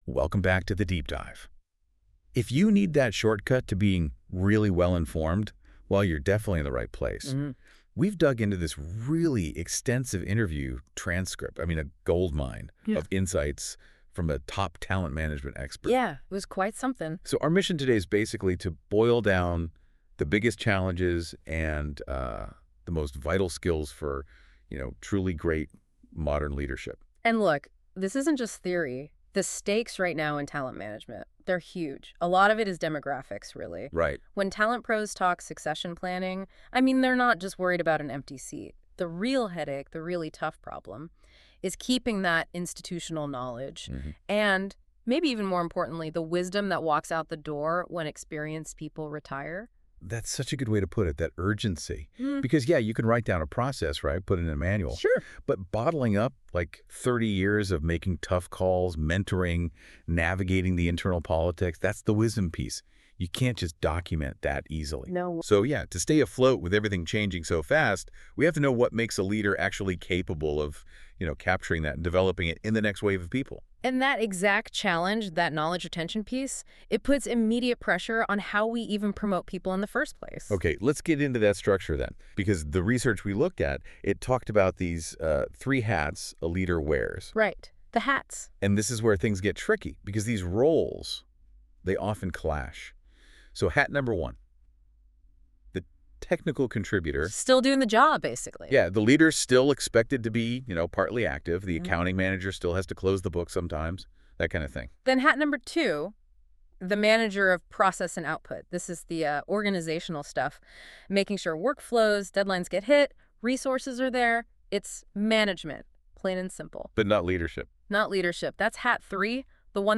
AI Recap Of The Conversation